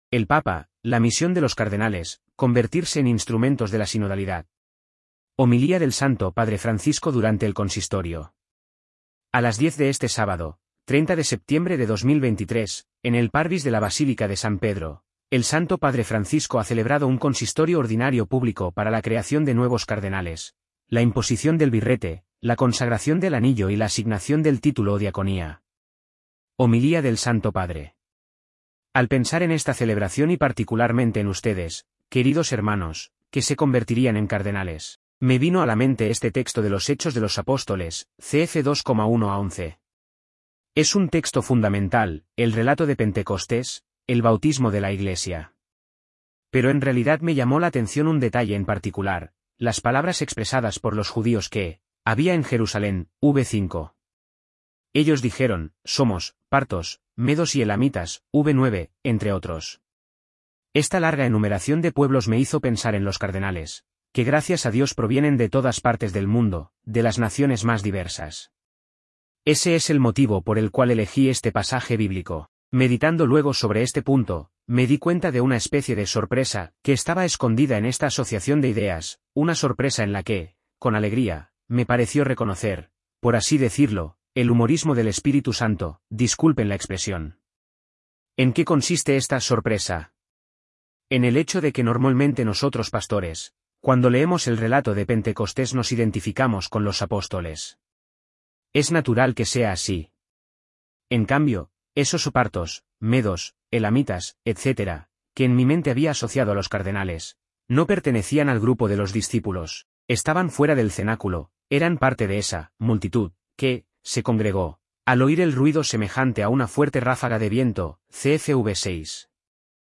Homilía del Santo Padre Francisco durante el Consistorio
Homilia-Sinodo.mp3